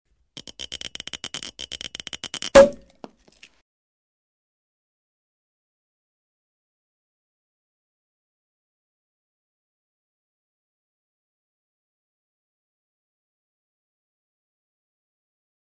Шум открытия шампанского и хлопок пробки